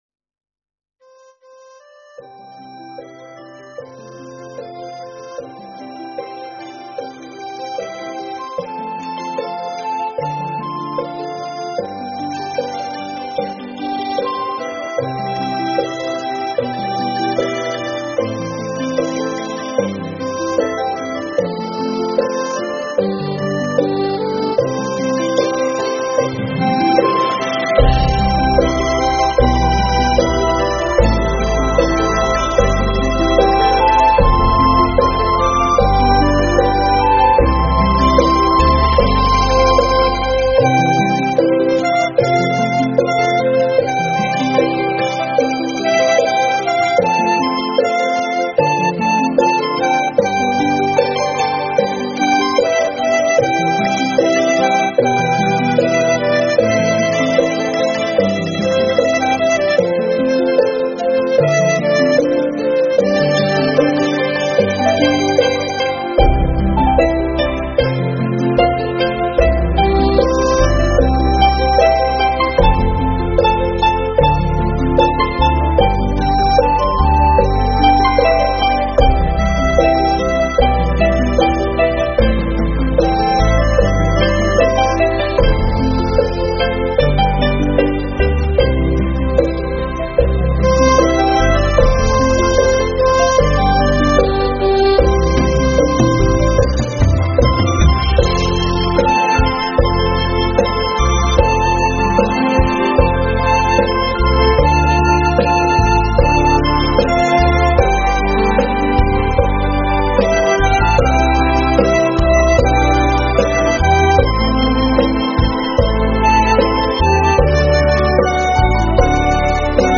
Mp3 thuyết pháp Bốn Điều Không Thể